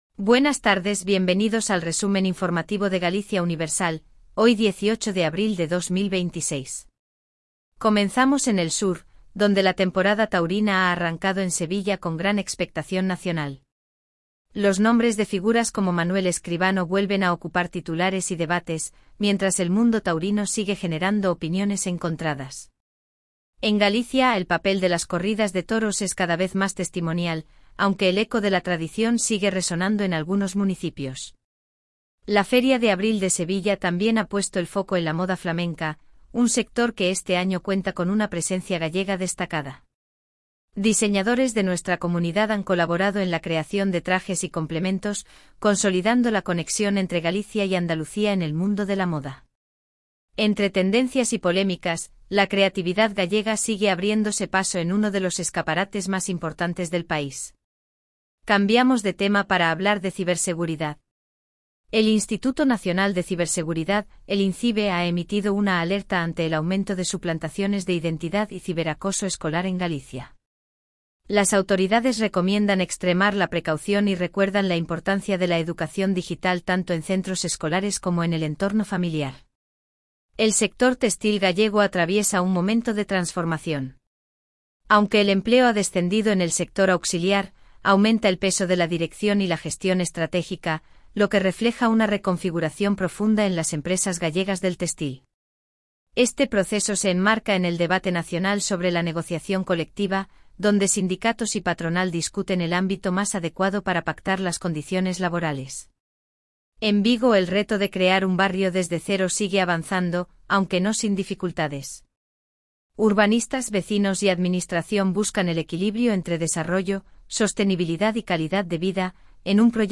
Resumen informativo de Galicia Universal